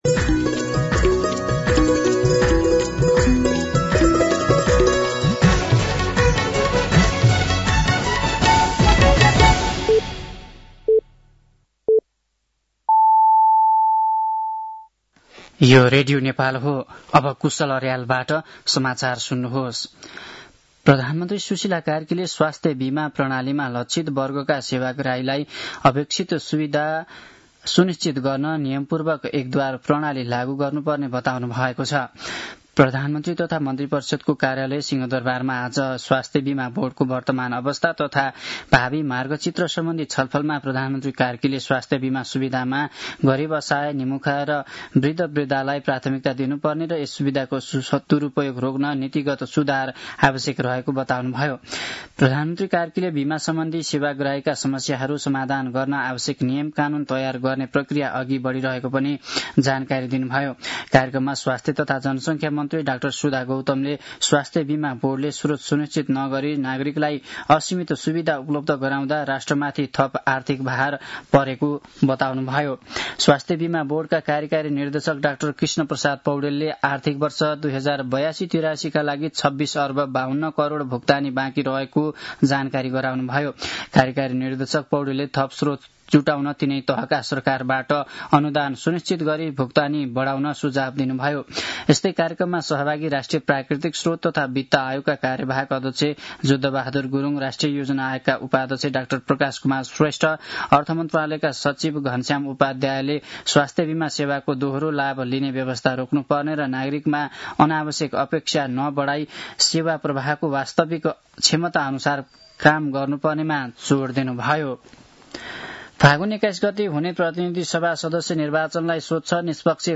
साँझ ५ बजेको नेपाली समाचार : २१ माघ , २०८२
5-pm-news-10-21.mp3